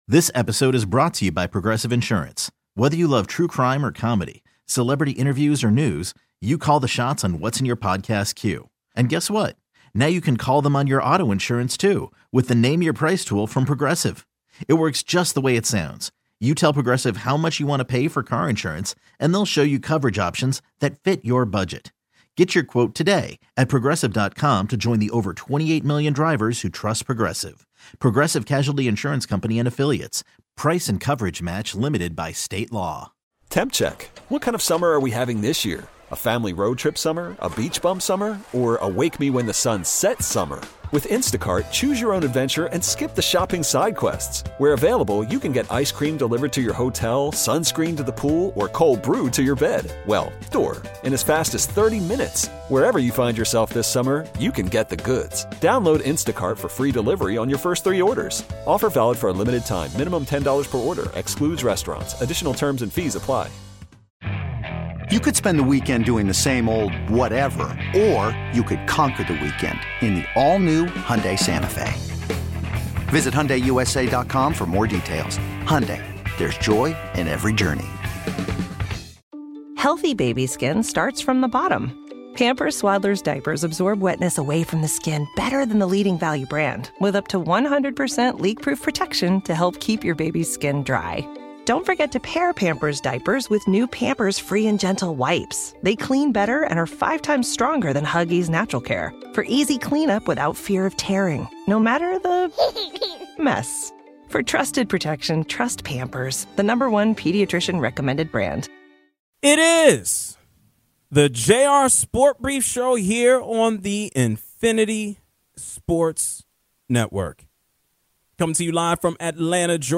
Celtics feeling the pressure l Adam Silver discusses the future of "Inside The NBA" l Funny caller is HYPED about the Mariners